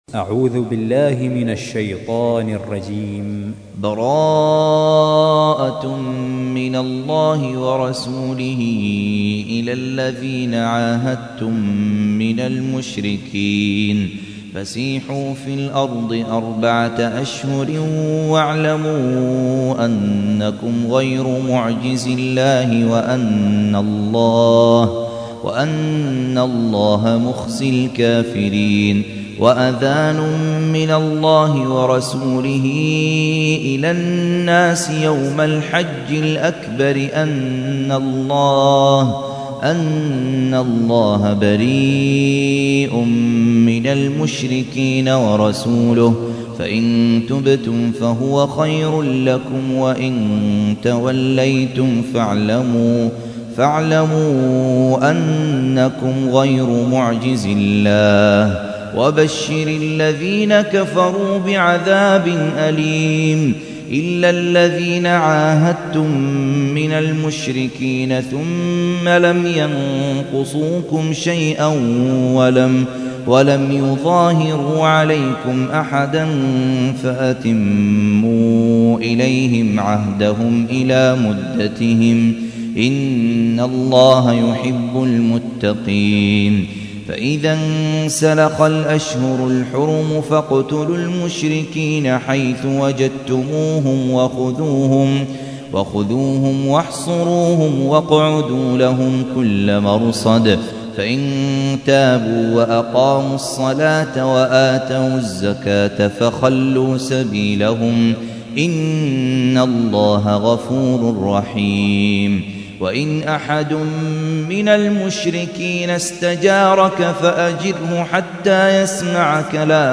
تحميل : 9. سورة التوبة / القارئ خالد عبد الكافي / القرآن الكريم / موقع يا حسين